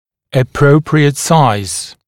[ə’prəuprɪət saɪz][э’проуприэт сайз]надлежащий размер